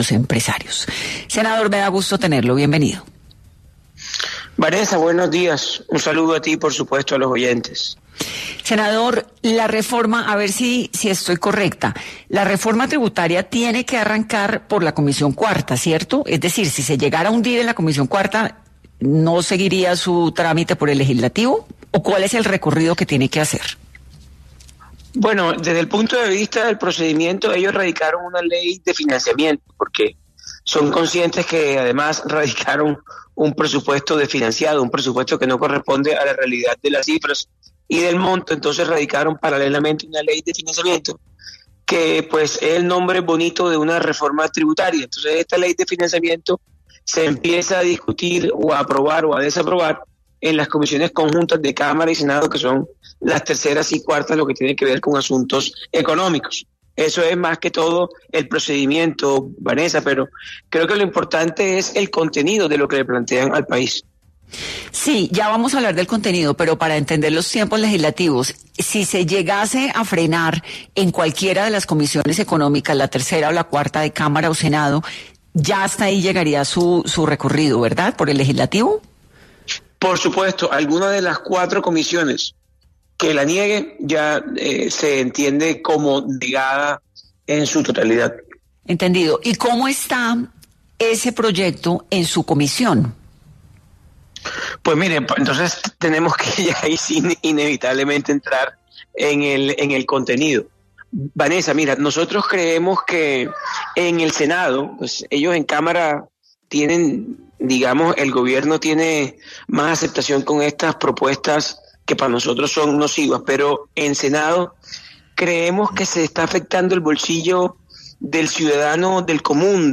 En una reciente entrevista con 10AM de Caracol Radio, el senador Carlos Meisel, del Centro Democrático e integrante de la Comisión Cuarta del Senado, expresó su preocupación por la reforma tributaria propuesta por el gobierno de Gustavo Petro.